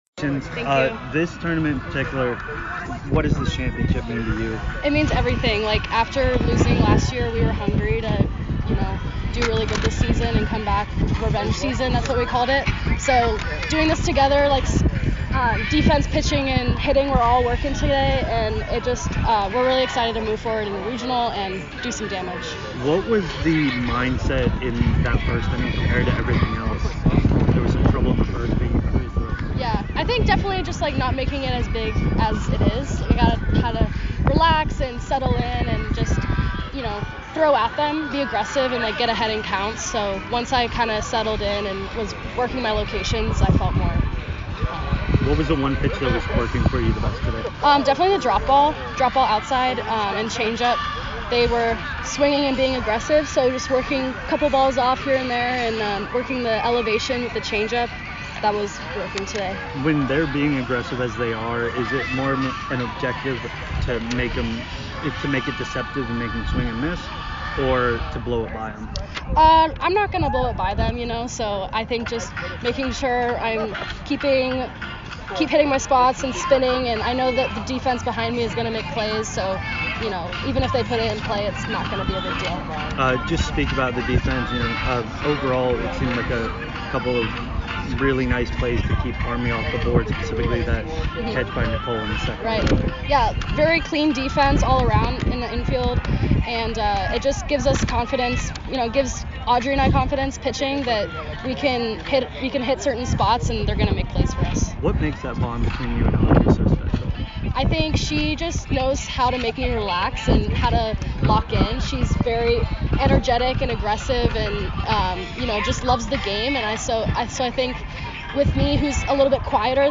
Army Postgame Interview